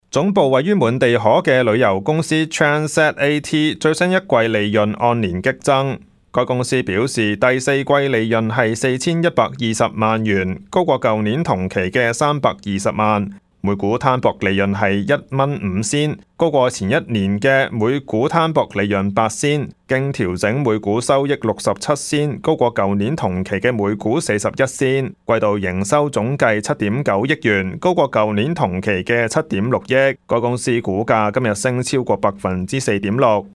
news_clip_21700.mp3